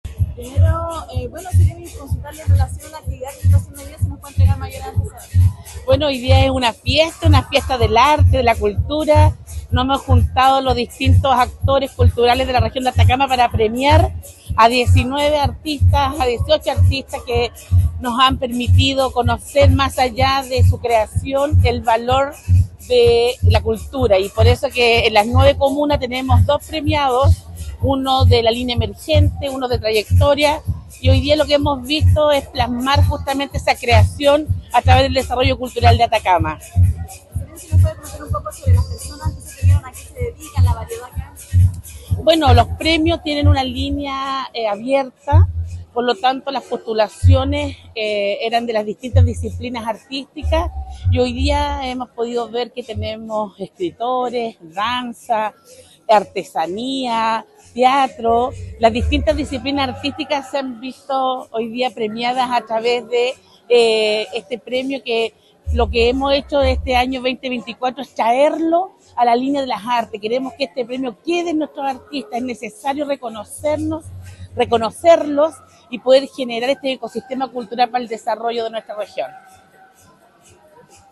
Carolina-Armenakis-Seremi-de-las-Culturas-las-Artes-y-Patrimonio-audio.mp3